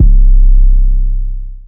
YM 808 6.wav